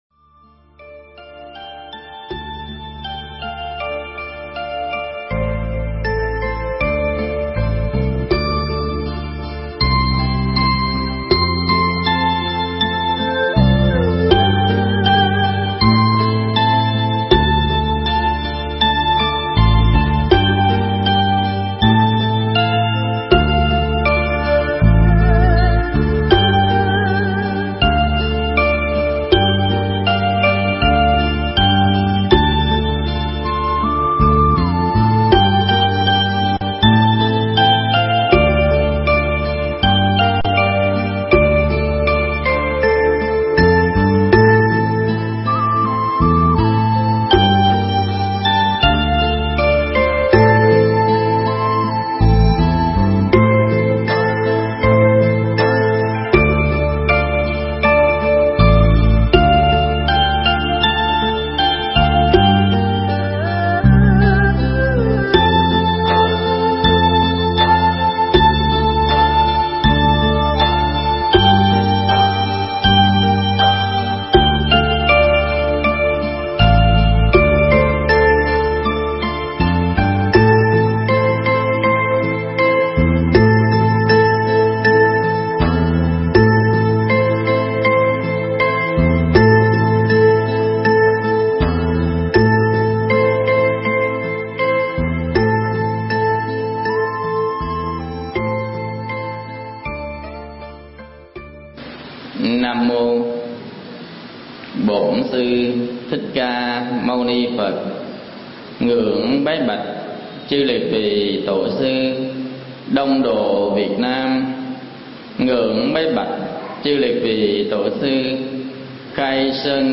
Nghe Mp3 thuyết pháp Đạo lý ta bà và tịnh độ
thuyết giảng tại Chùa Long Hưng – Lạc Long Quân ,Quận Tân Bình